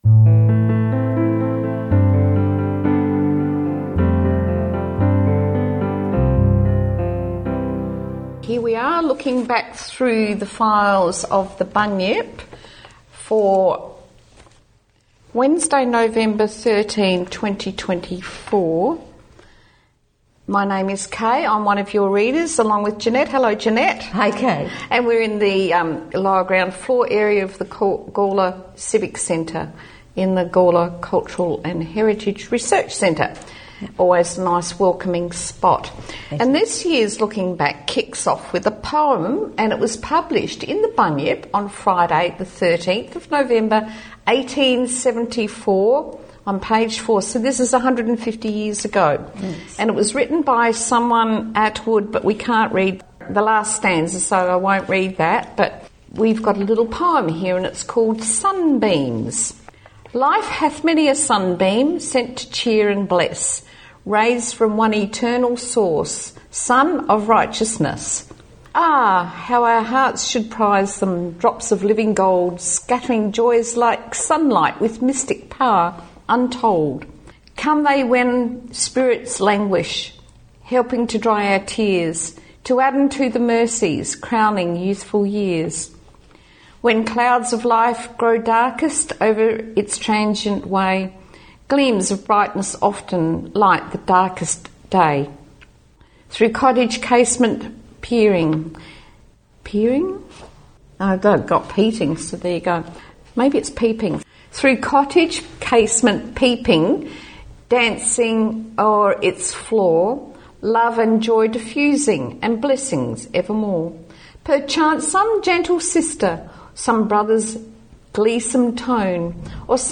Opening and closing music taken from A Tribute to Robbie Burns by Dougie Mathieson and Mags Macfarlane